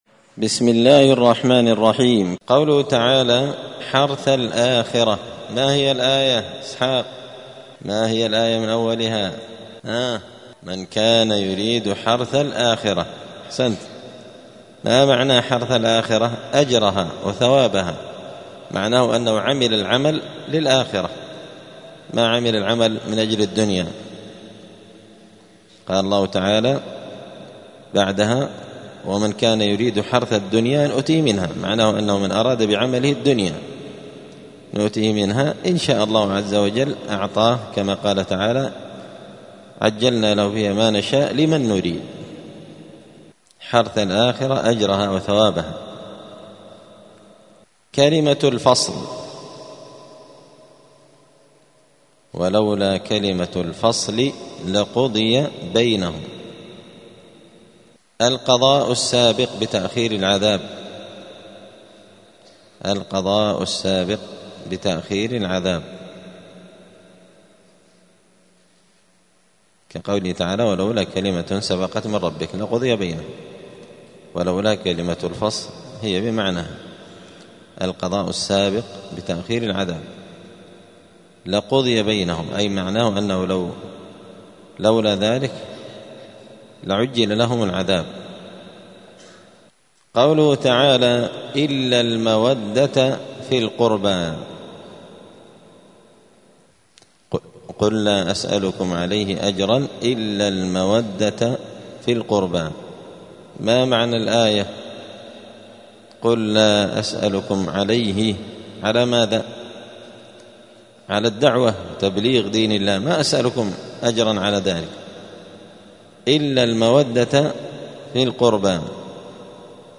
الأربعاء 17 جمادى الآخرة 1446 هــــ | الدروس، دروس القران وعلومة، زبدة الأقوال في غريب كلام المتعال | شارك بتعليقك | 26 المشاهدات